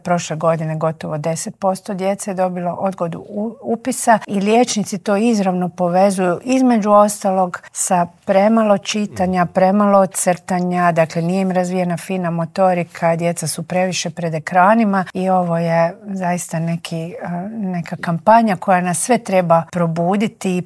Nacionalni čitalački izazov "15 po 15 - cijela Hrvatska čita djeci" polučio je odlične rezultate, otkrila je u Intervjuu tjedna Media servisa ministrica kulture i medija Nina Obuljen Koržinek.